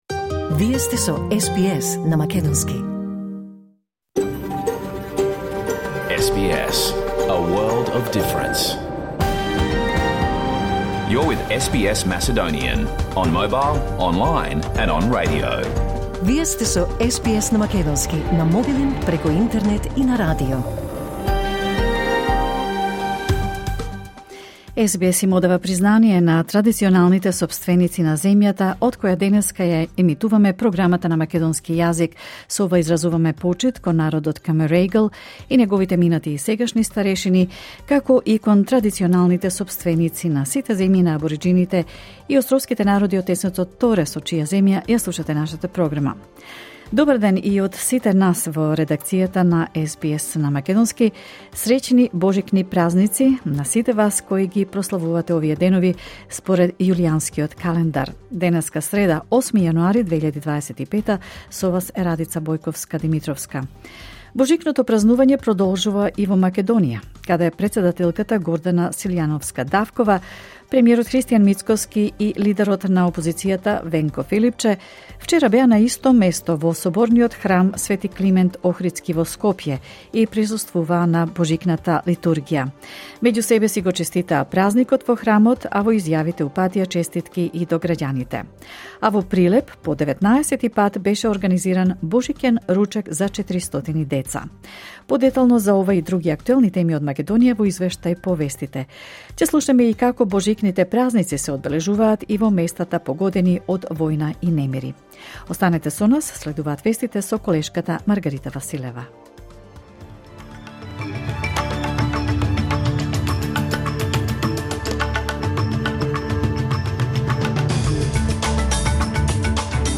SBS Macedonian Program Live on Air 8 January 2025